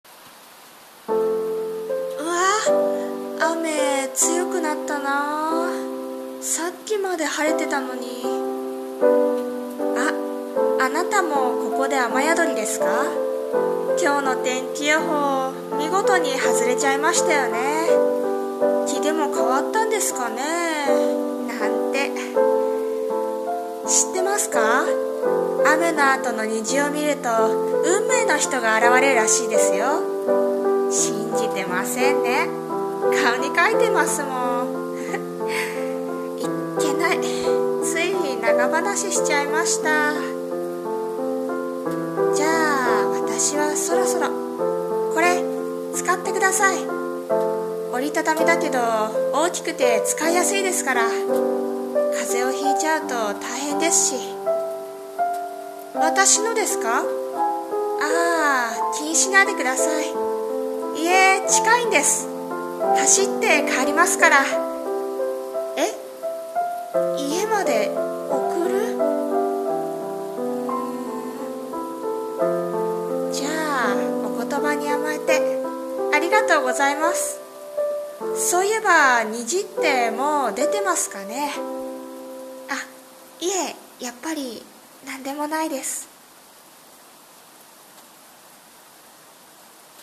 さんの投稿した曲一覧 を表示 【声劇】 雨宿り